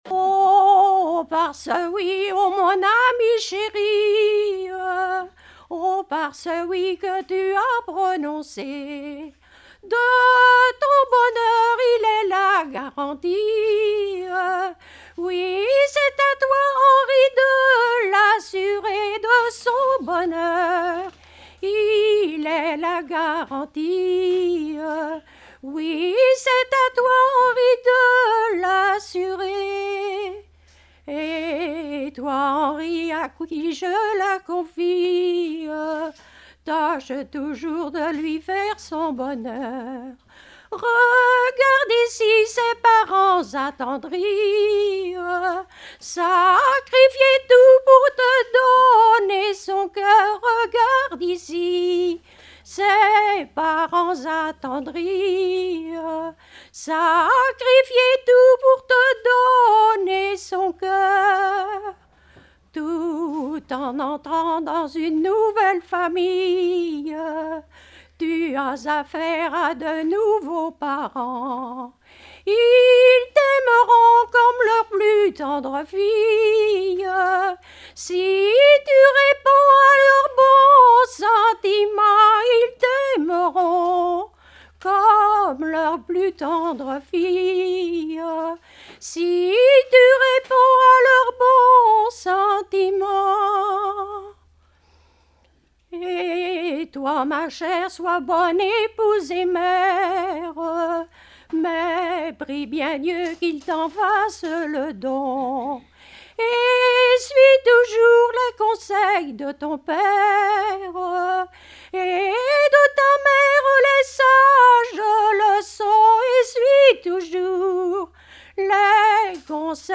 circonstance : fiançaille, noce
Genre strophique
Pièce musicale inédite